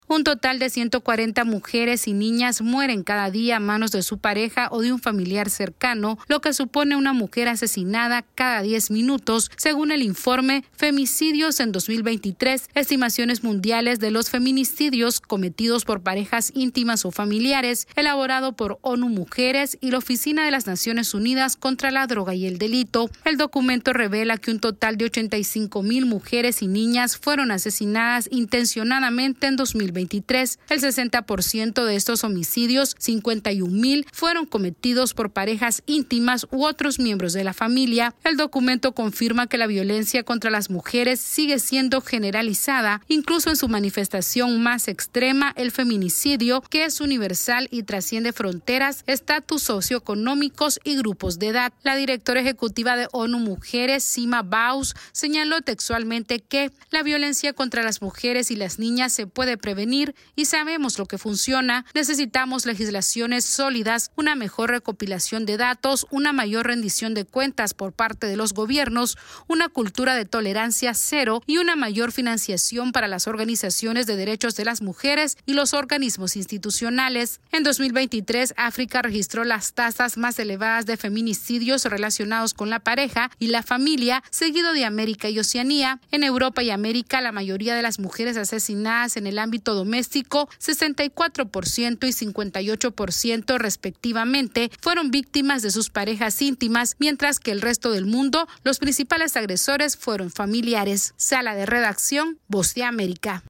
Esta es una actualización de nuestra Sala de Redacción....